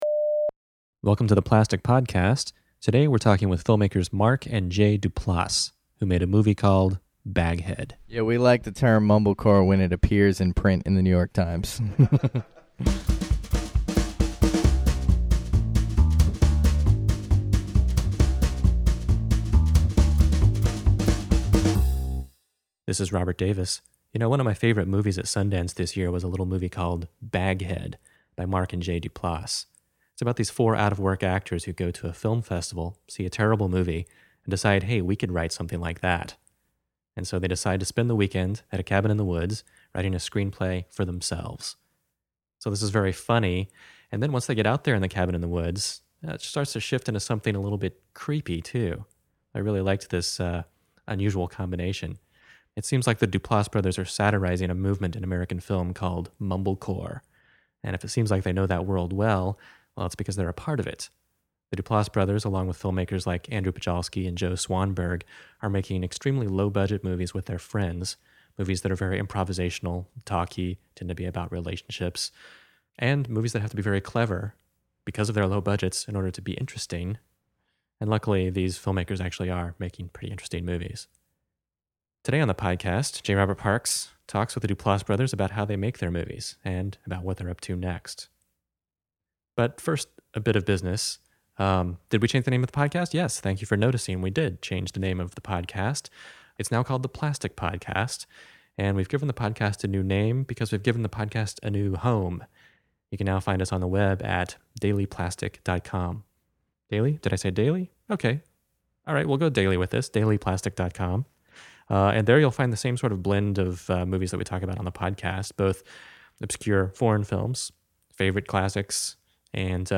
We sat down with the brothers Duplass in an exceedingly warm Chicago conference room, and the conversation revolved around the process of writing and editing, why watching movies at night is a different experience, and the enduring classic that is Fletch.
Interview: Mark and Jay Duplass